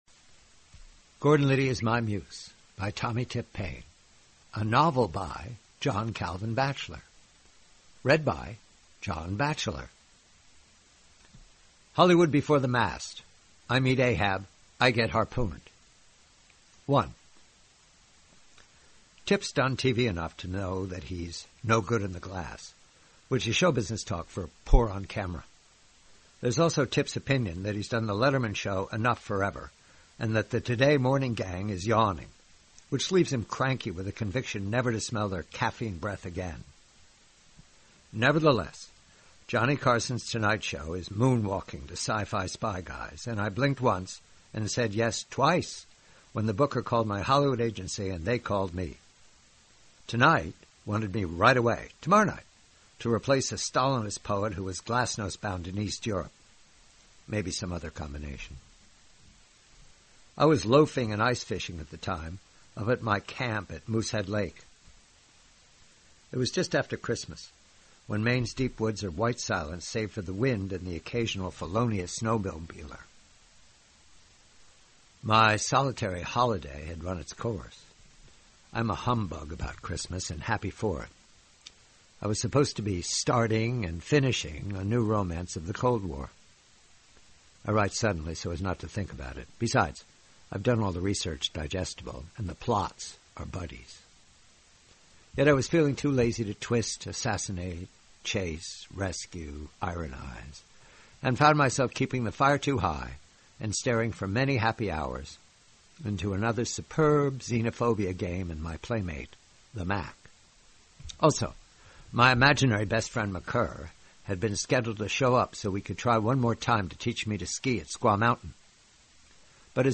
Read by the host.